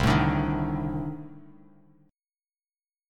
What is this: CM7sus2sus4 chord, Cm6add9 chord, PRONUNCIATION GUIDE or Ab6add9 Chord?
Cm6add9 chord